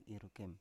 Dialek: Biak Utara